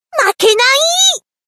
Skill activation